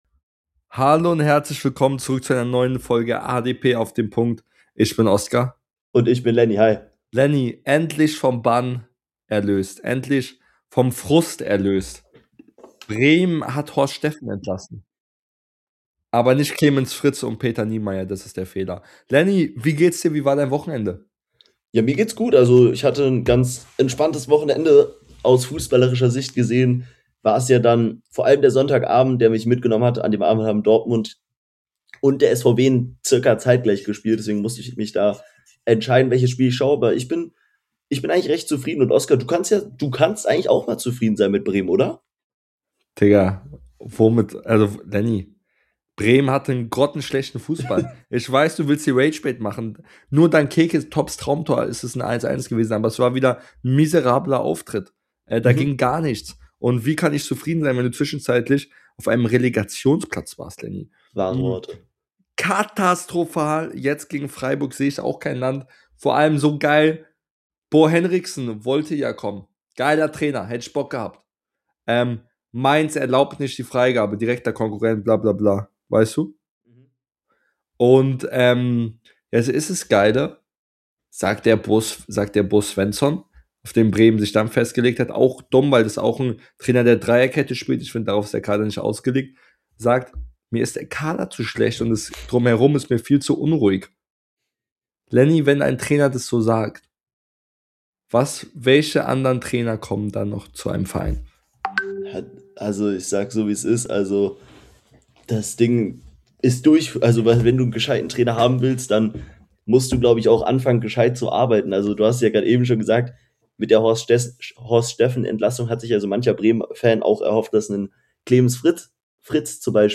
In der heutigen Folge sprechen die beiden Hosts über alles rund um Werders Trainer Entlassung und Suche , loben den HSV ,diskutieren über den DFB Pokal und vieles mehr